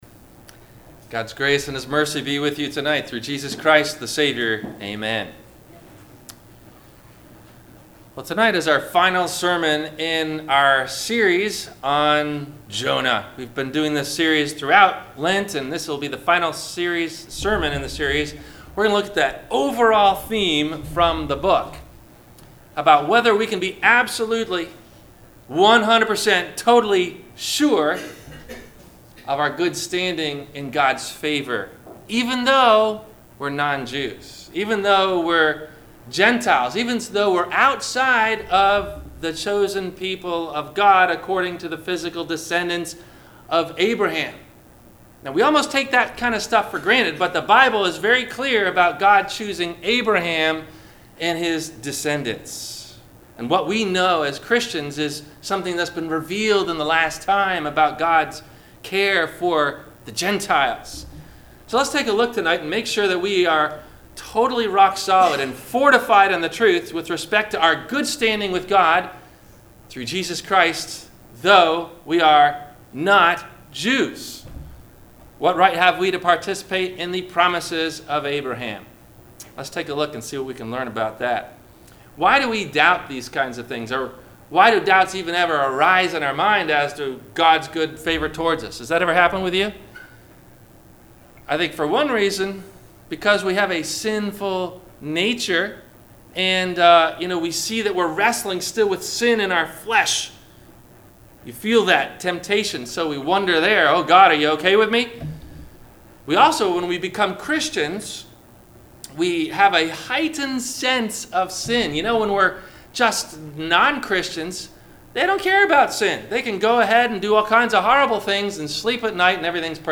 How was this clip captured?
God's Favor Toward The Gentiles - Good Friday - Sermon - April 19 2019 - Christ Lutheran Cape Canaveral